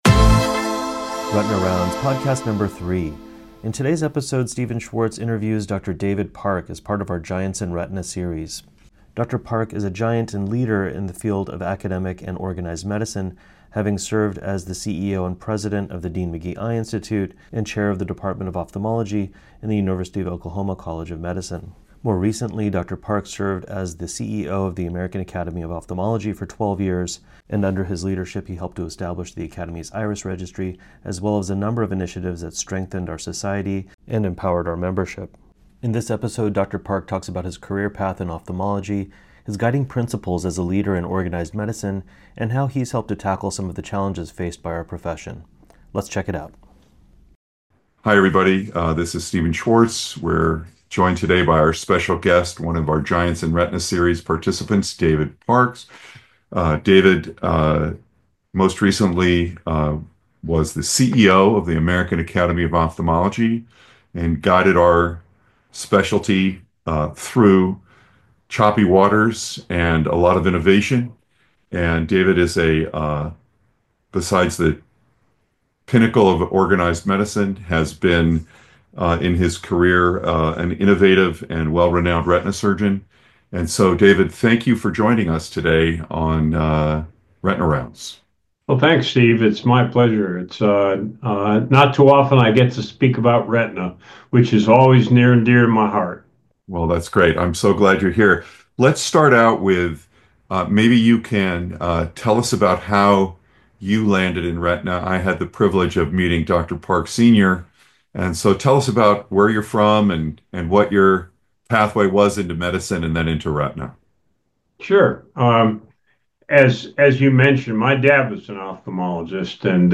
The interview provides a unique perspective on how leadership within organized medicine and ophthalmology can help to advance the field!